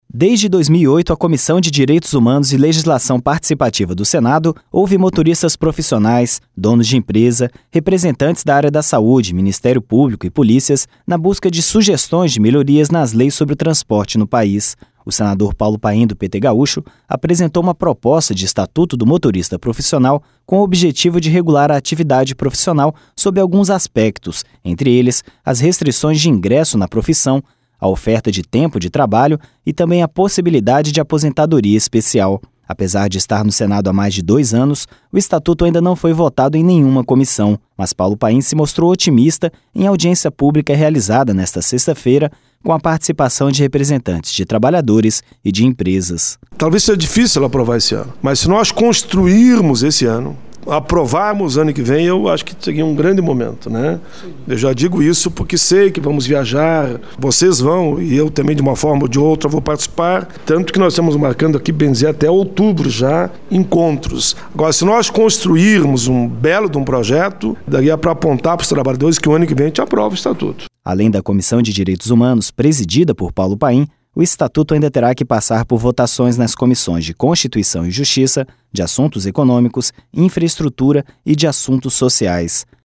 Mas Paulo Paim se mostrou otimista em audiência pública realizada nesta sexta-feira com a participação de representantes de trabalhadores e de empresas.